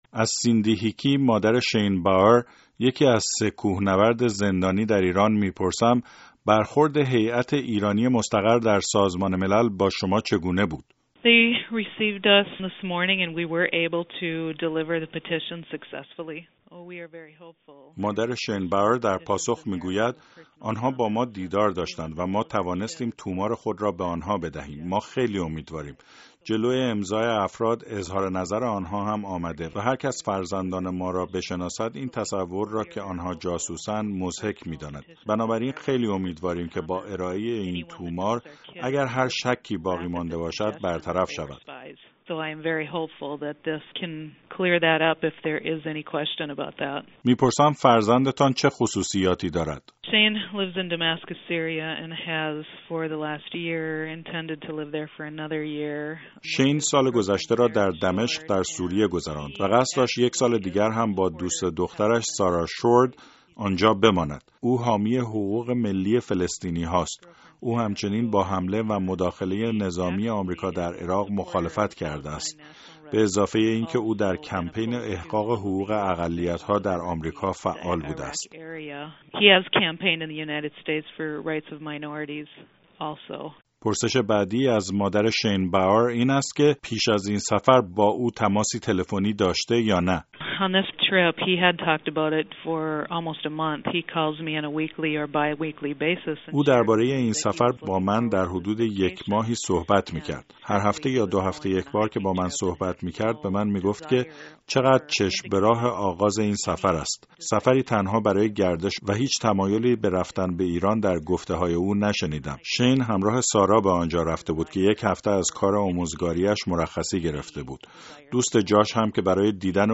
گفتگوی رادیو فردا با مادران آمریکایی های بازداشتی در ایران